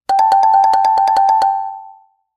場面転換・オープニング・エンディング
場面転換５/可愛い